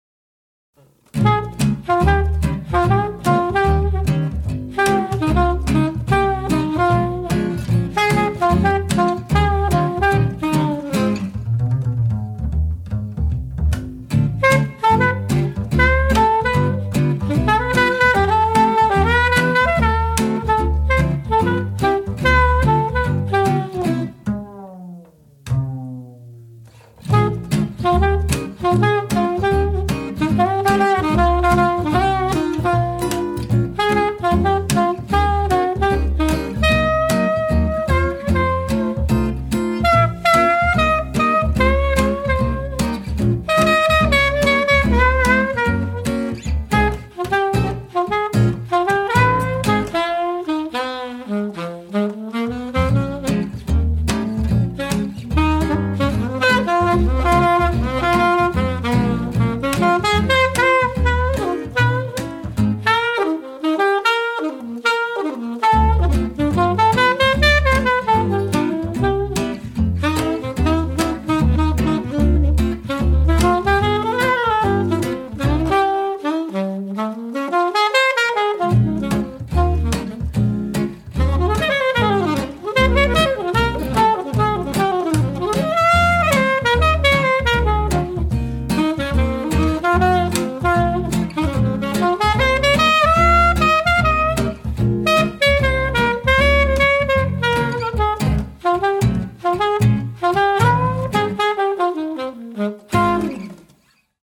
• Cool, exciting, joyful, and relaxing sound